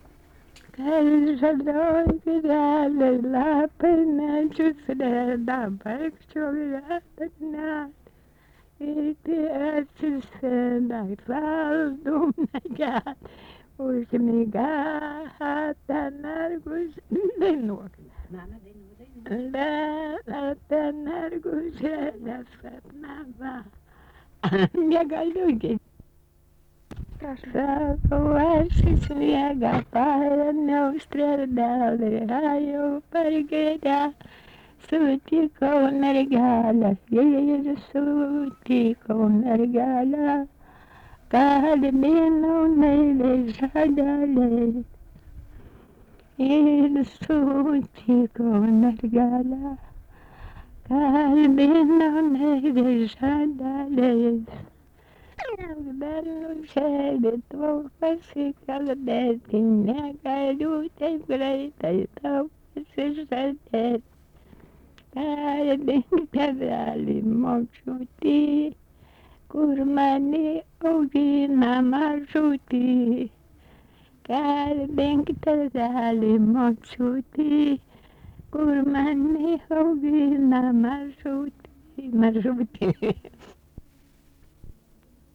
Tipas daina Erdvinė aprėptis Aleksandravėlė (Rokiškis)
Atlikimo pubūdis vokalinis